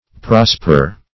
Prosper \Pros"per\, v. i.